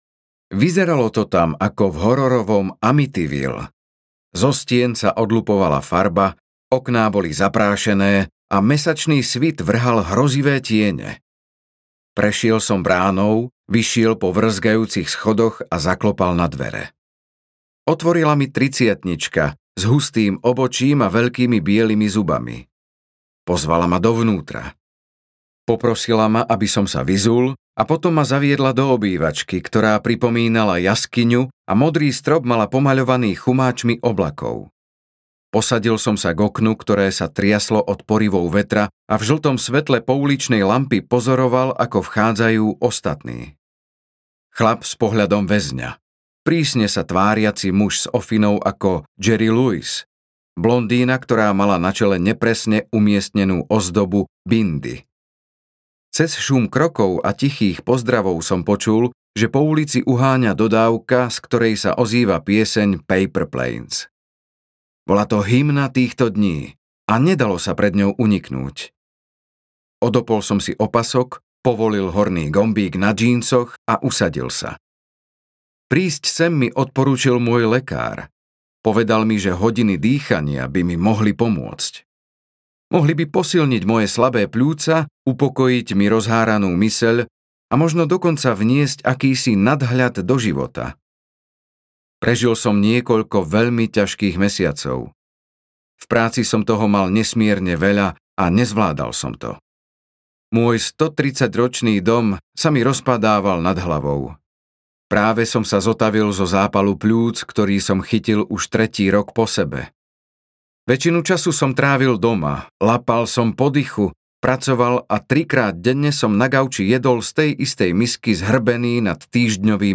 Dych audiokniha
Ukázka z knihy